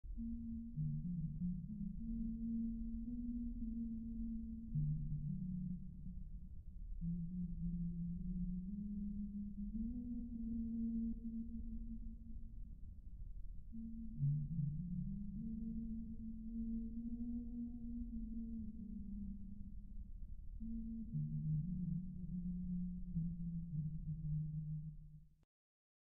ghost noise